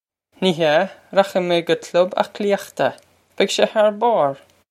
Pronunciation for how to say
Nee ha! Rokh-ig may guh klub ok-lee-ukht-ah. Beg shay har bawr!
This is an approximate phonetic pronunciation of the phrase.